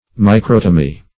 Search Result for " microtomy" : The Collaborative International Dictionary of English v.0.48: Microtomy \Mi*crot"o*my\, n. The art of using the microtome; investigation carried on with the microtome.